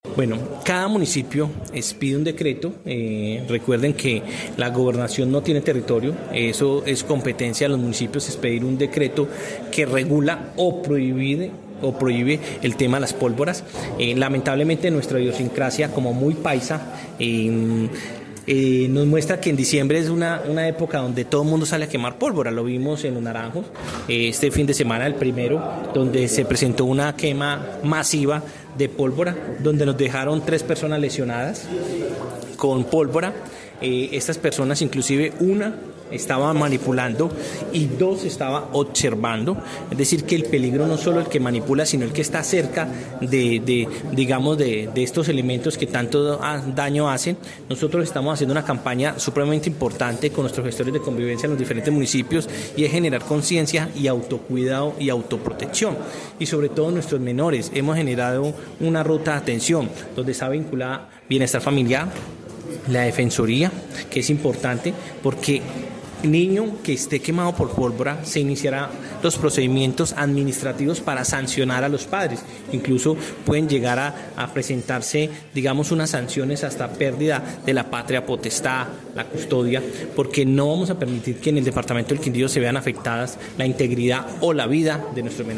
Jaime Andrés Pérez, secretario del interior del Quindío